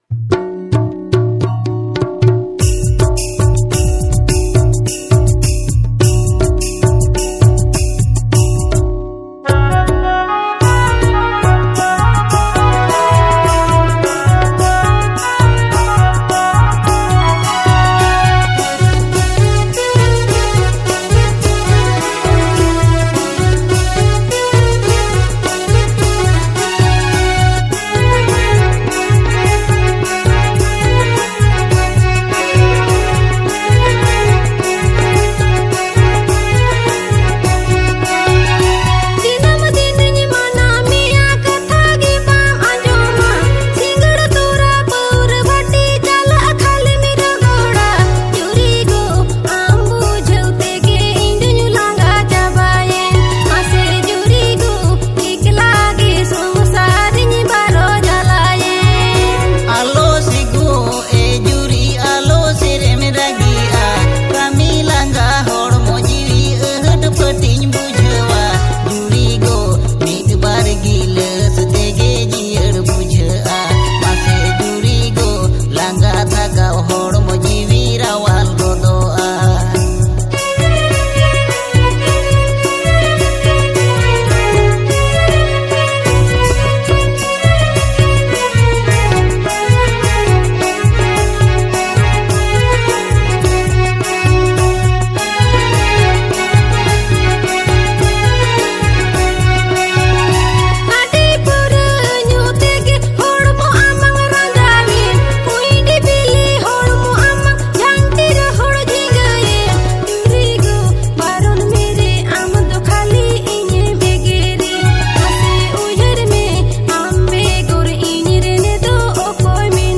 Santali song